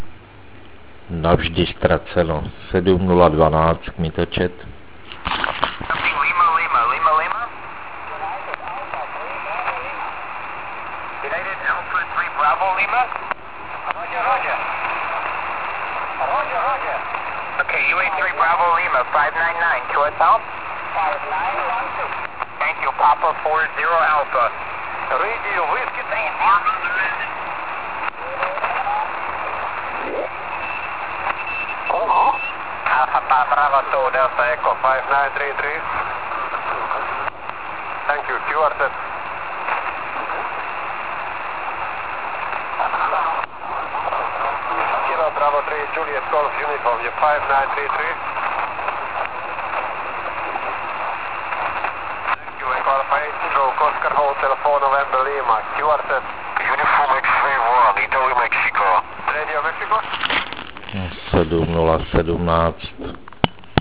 40m scan1 (*.wav 250 kB)    40m scan2 (*.wav 250 kB)